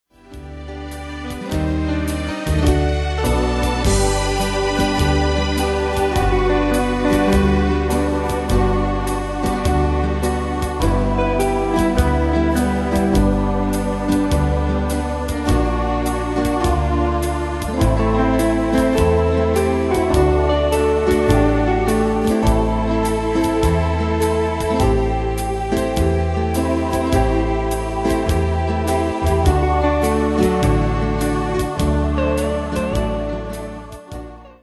Style: Oldies Ane/Year: 1945 Tempo: 103 Durée/Time: 2.56
Danse/Dance: Foxtrot Cat Id.
Pro Backing Tracks